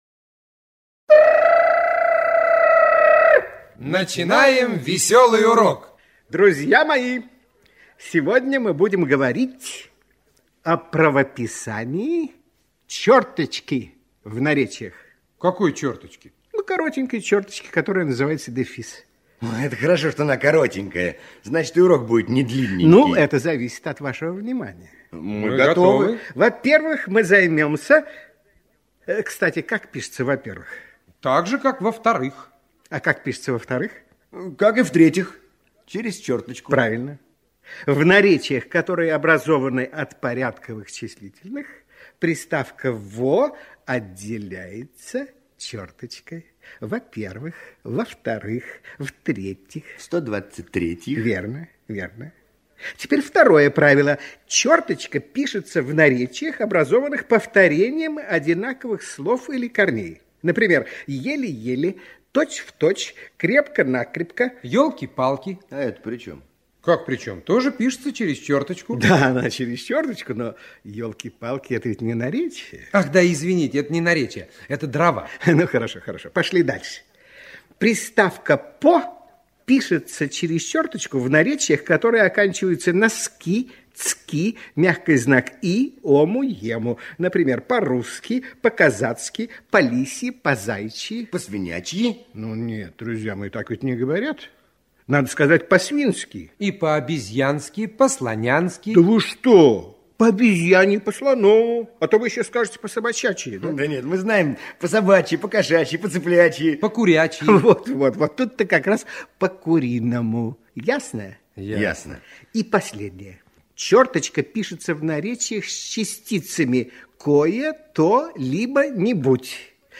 В этом разделе размещены аудиоуроки для школьников из образовательной программы «Радионяня», которая транслировалась на всесоюзном радио в 1970-1980 г.
«Весёлые уроки радионяни» в шутливой музыкальной форме помогают детям запомнить правила русского языка..